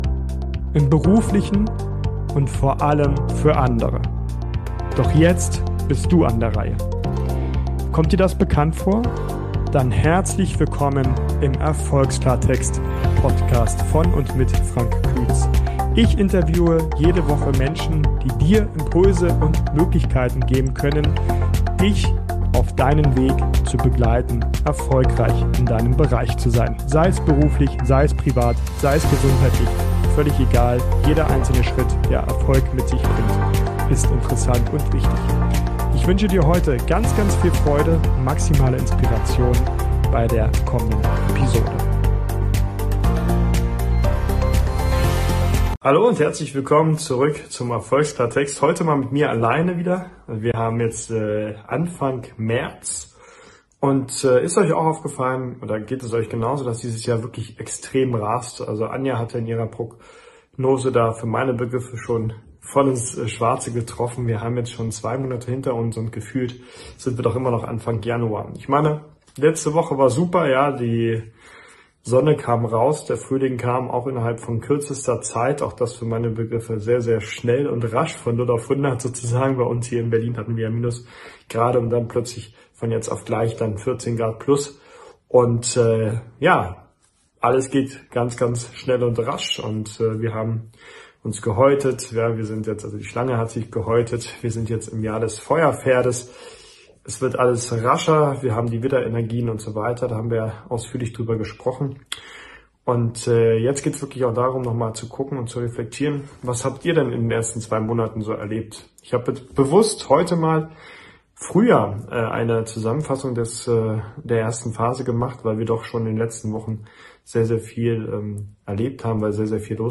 Solo Folge